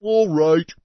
PeasantYes3.mp3